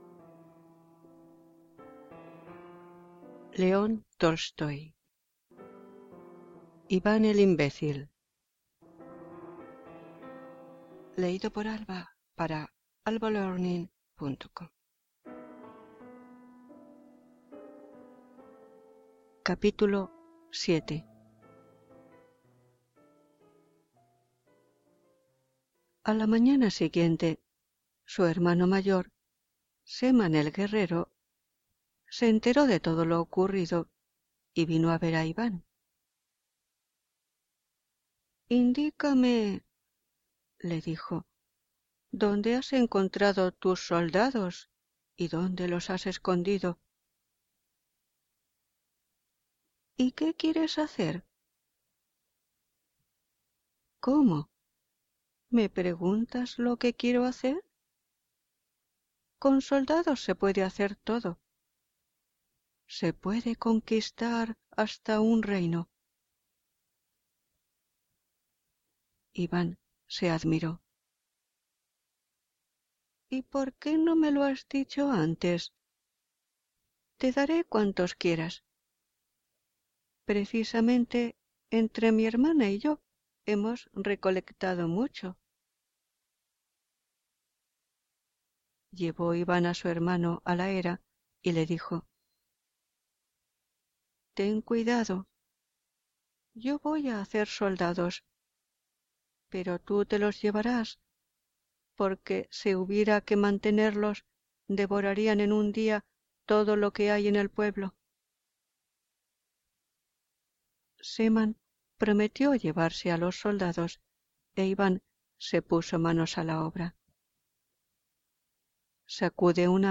Iv�n el imb�cil (Cap. 7) - Le�n Tolstoi - AlbaLearning Audiolibros y Libros Gratis
Música: Chopin - Op.34 no.2, Waltz in A minor